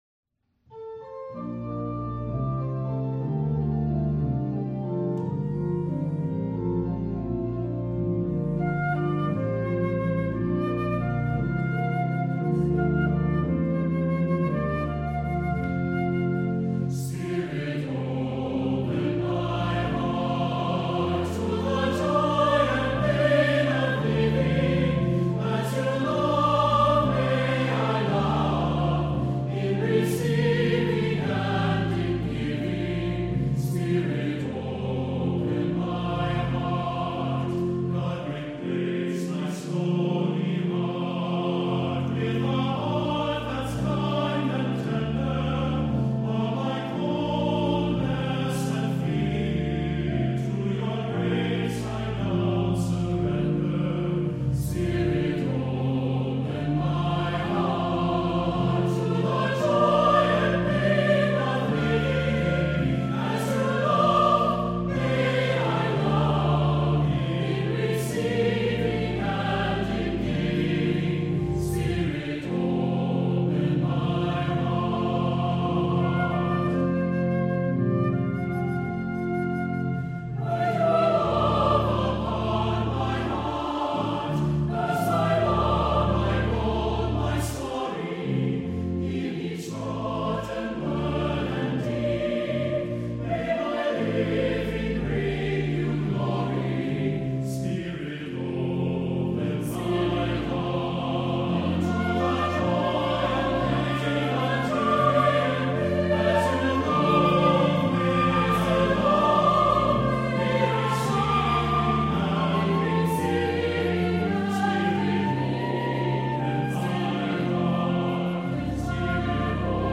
is a tender setting of the traditional Irish melody